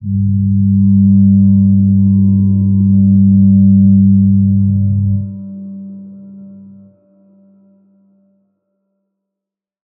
G_Crystal-G3-mf.wav